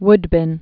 (wdbĭn)